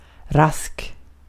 Ääntäminen
US : IPA : ['ræp.ɪd] RP : IPA : /ˈɹæpɪd/